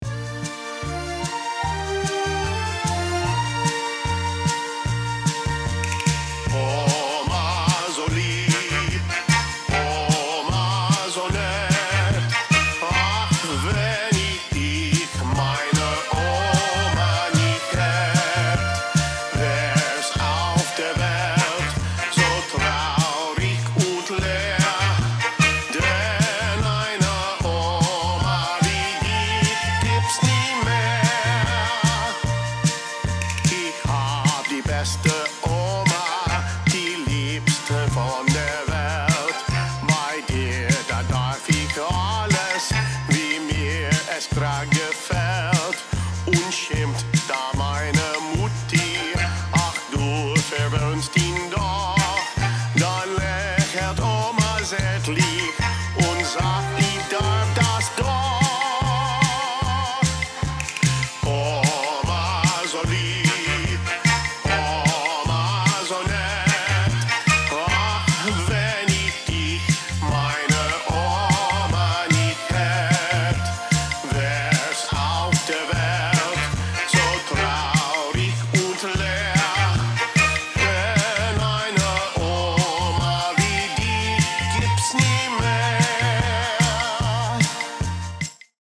Opera / Theatrical